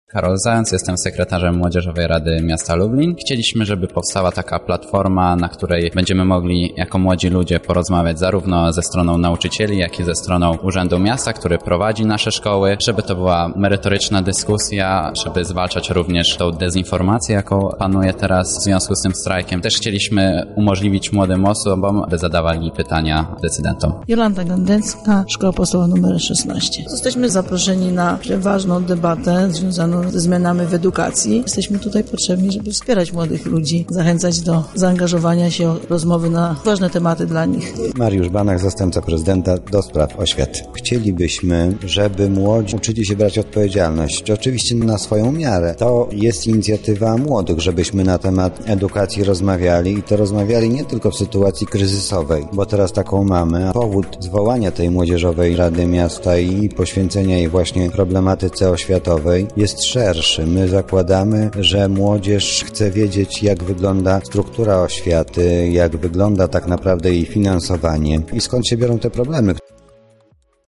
Na miejscu był nasz reporter:
Relacja